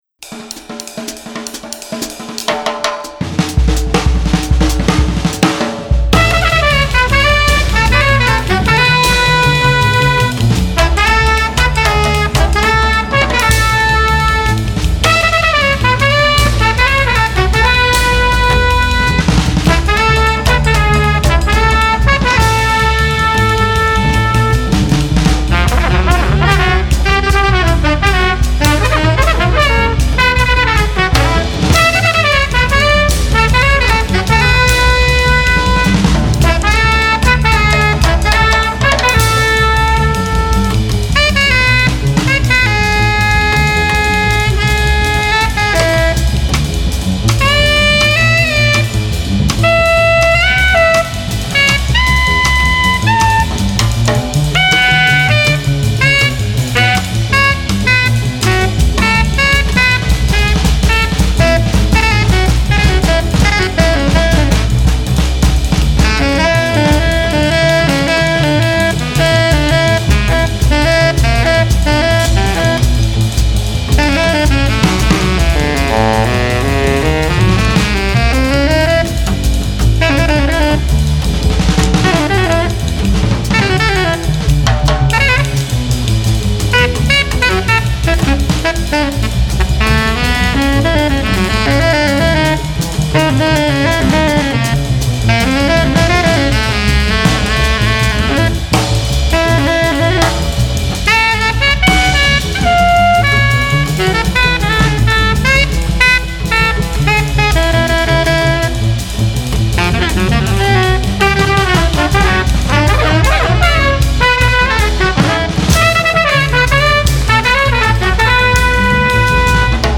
at Capitol Records Studios in Hollywood last spring
Trumpet/Flugelhorn/Melodica
Tenor Sax/Clarinet/Melodica
Drums/Cymbals